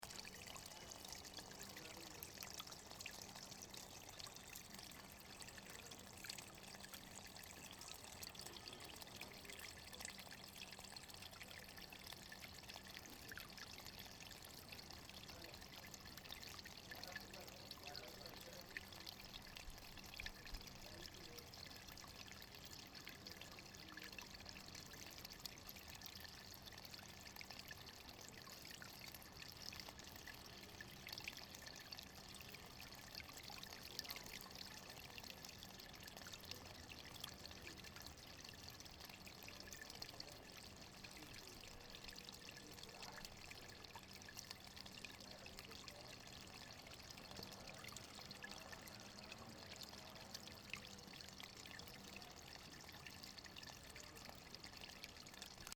At the bottom of an alley, on the top of a marble stair, I discover an heavenly place, with a wonderful view on the lac and on the city.
fontaine.mp3